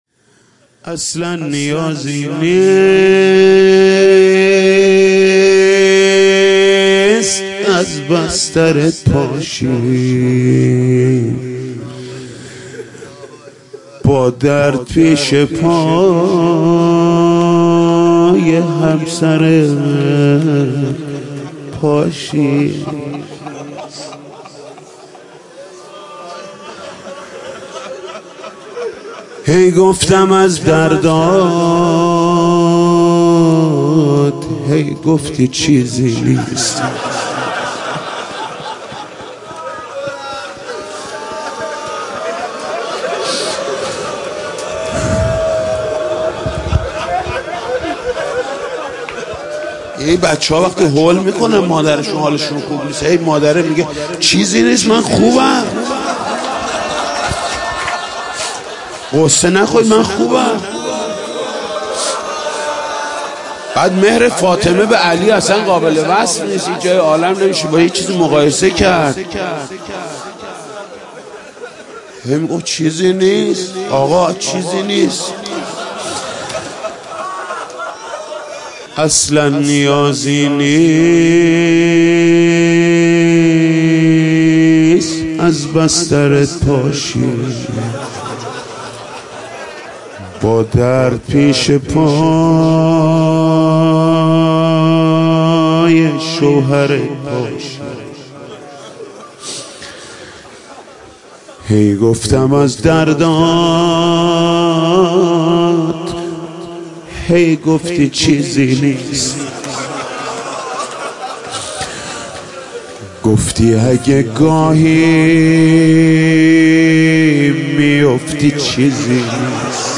دانلود مداحی شهادت حضرت زهرا
شب چهارم ایام فاطمیه
روضه ایام فاطمیه